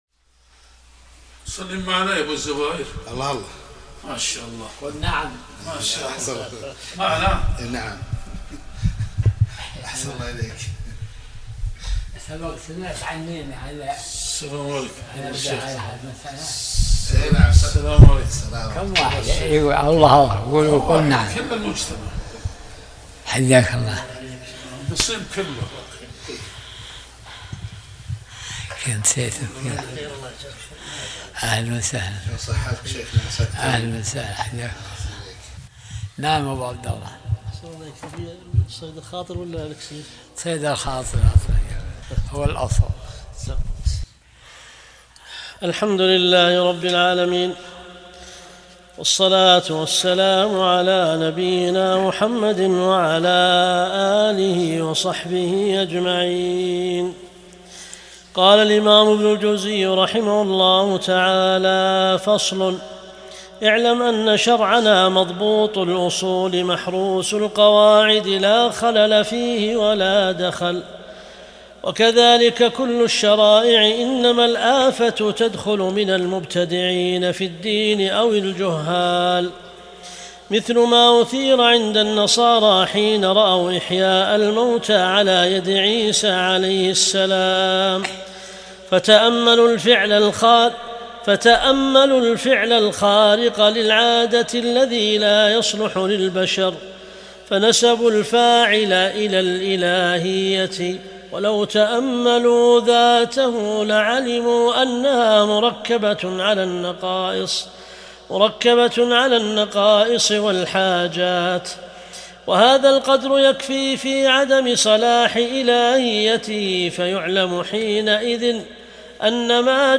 درس الأربعاء 68